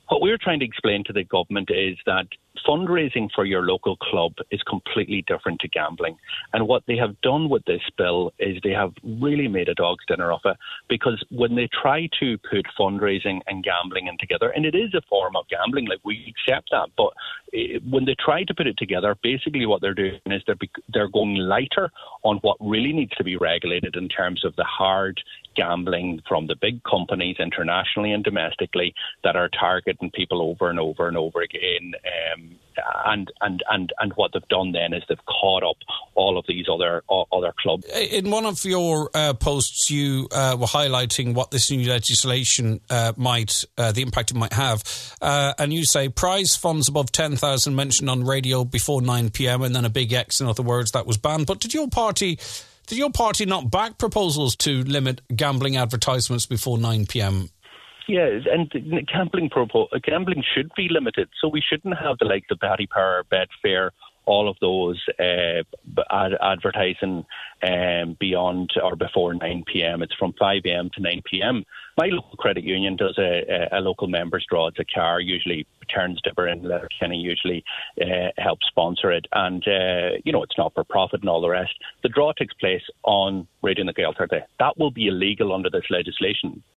Deputy Pearse Doherty says as a result, more problematic gambling marketing material could be overlooked: